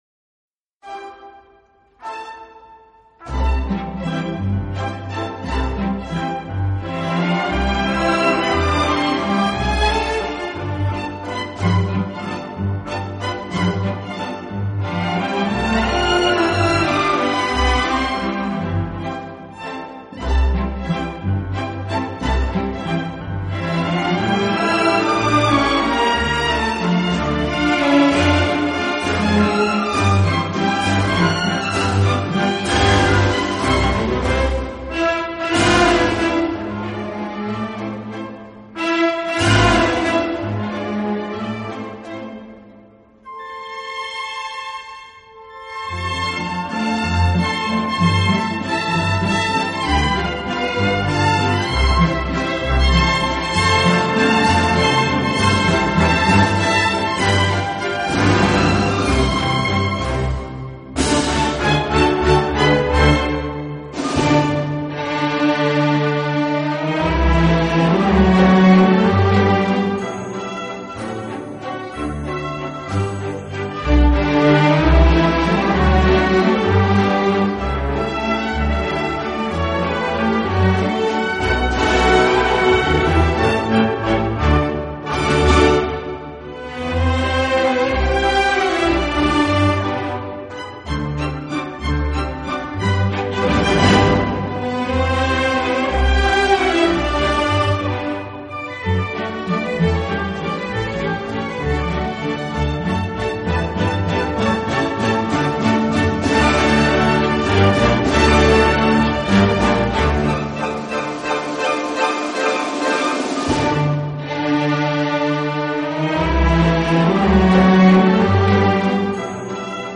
音乐类型：Instrumental